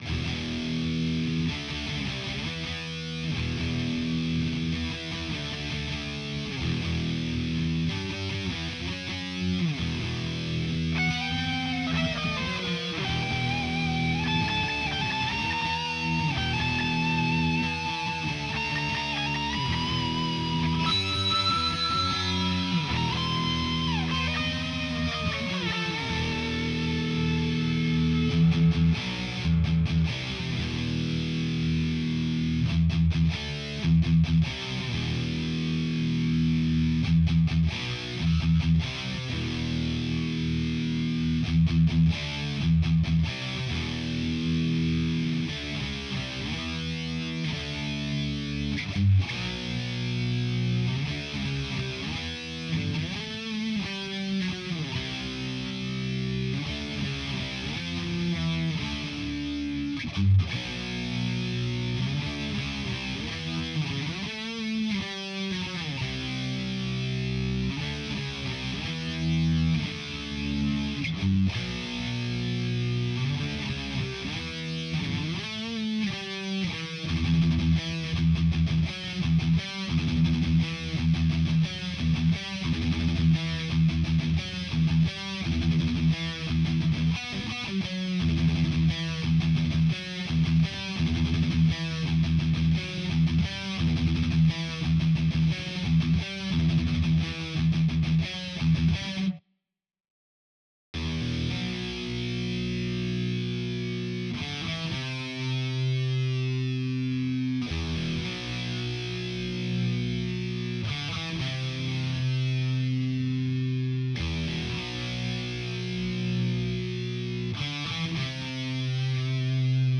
Şuanda tonum aşşağıdaki gibidir.